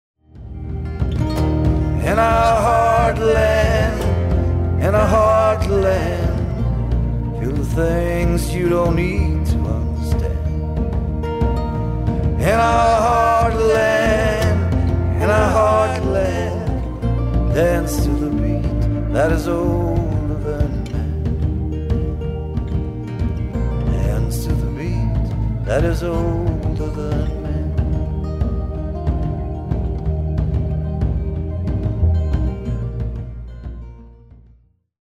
drums
bass
organ and backing vocals